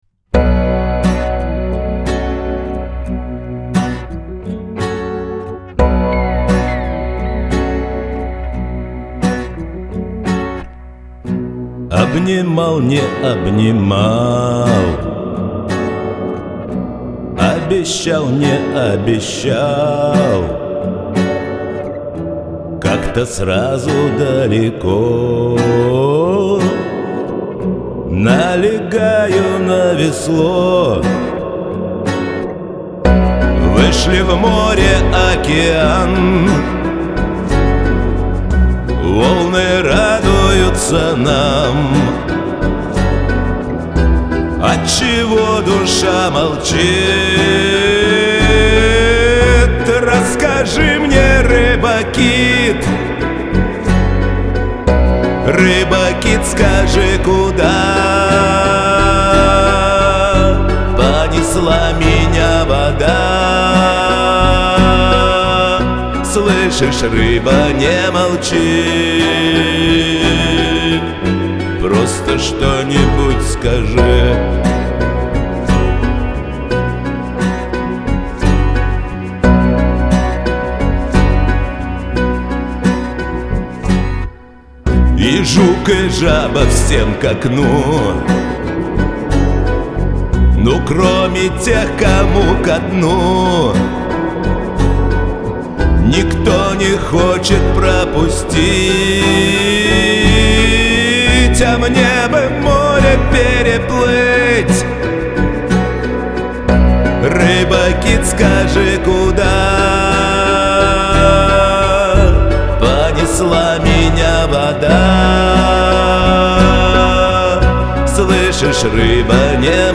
Жанр: Rock, Pop